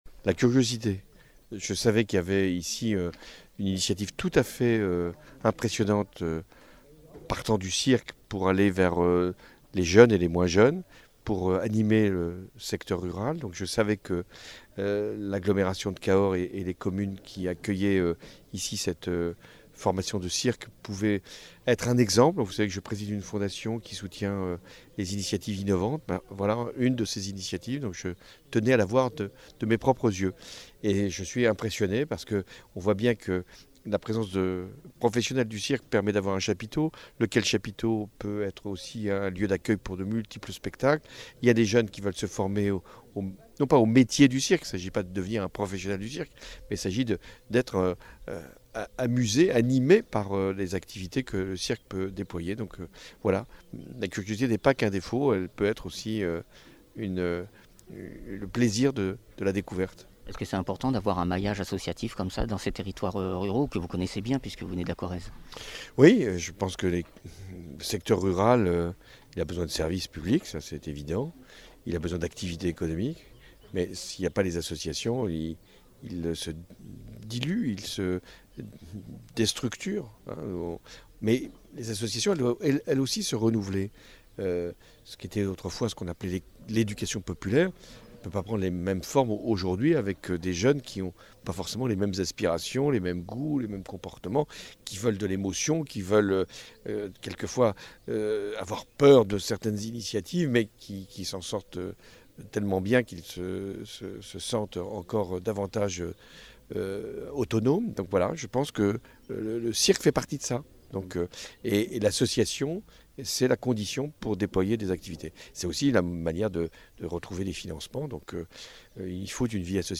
Interviews
Invité(s) : François Hollande, ancien Président de la république